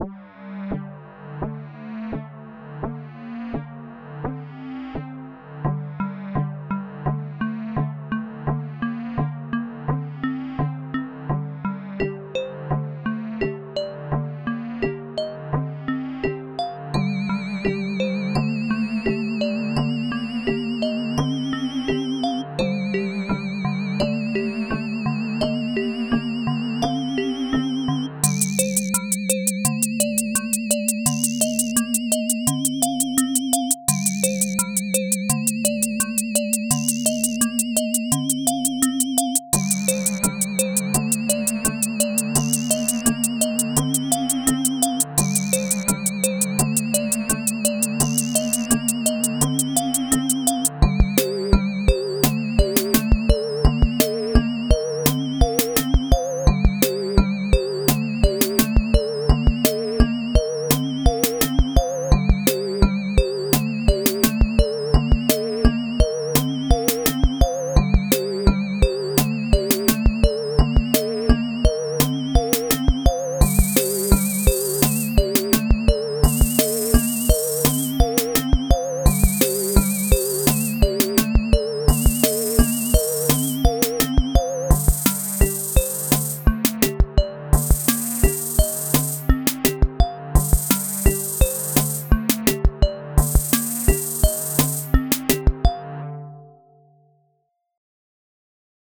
Pieza de música electrónica
Música electrónica
melodía
sintetizador